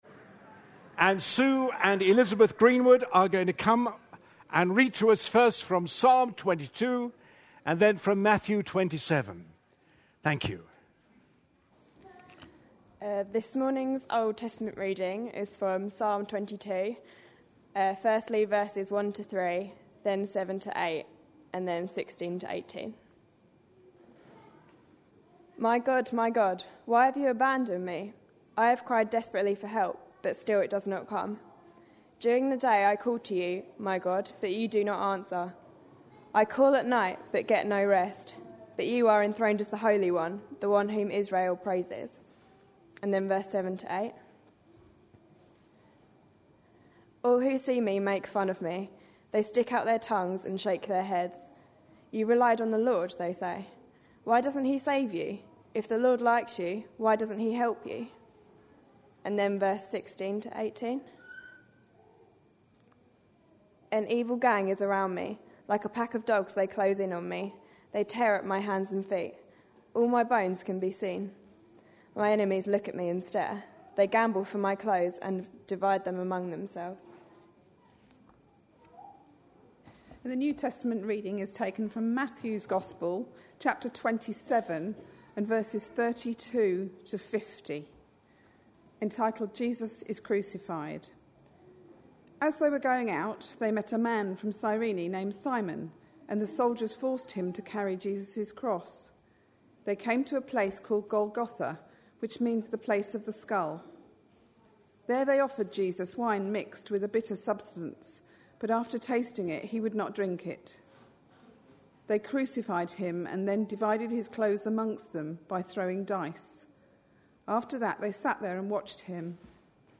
A sermon preached on 14th August, 2011, as part of our Psalms we Love series.